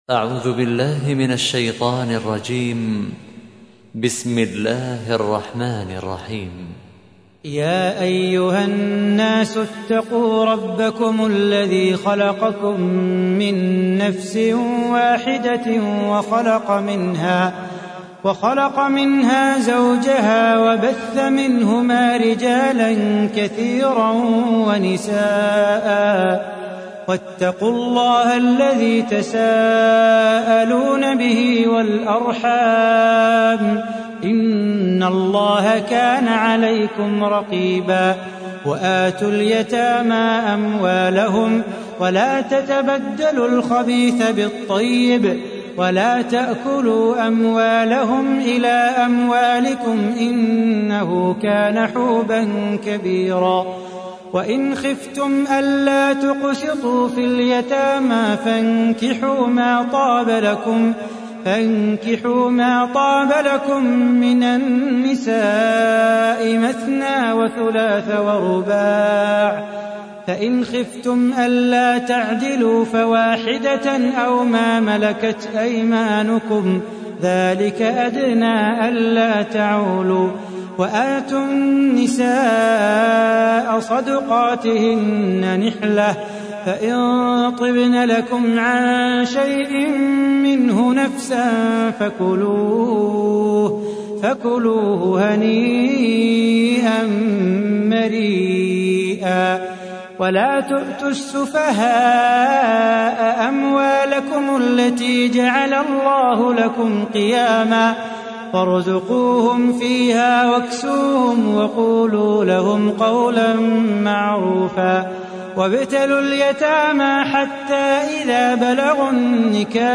تحميل : 4. سورة النساء / القارئ صلاح بو خاطر / القرآن الكريم / موقع يا حسين